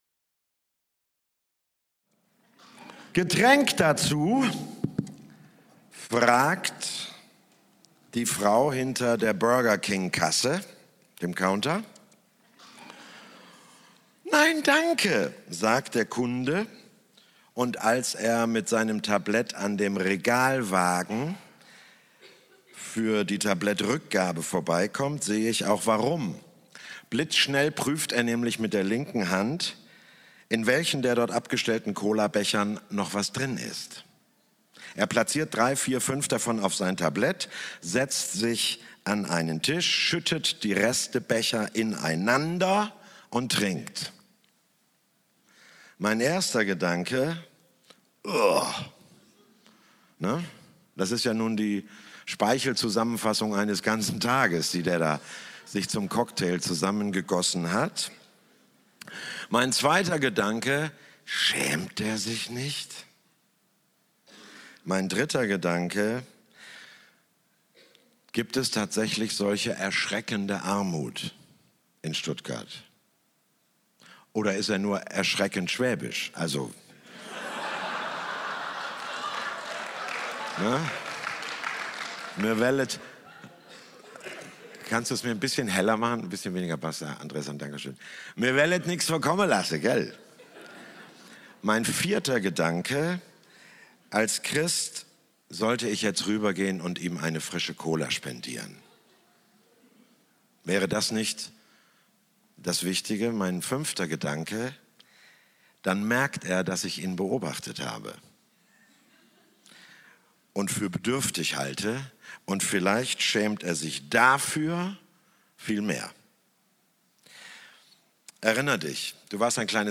RAD Tagung 2014 – DAS RAD
Tagungs-Zentrum Schönblick